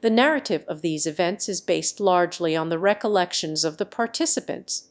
Matcha-TTS - [ICASSP 2024] 🍵 Matcha-TTS: A fast TTS architecture with conditional flow matching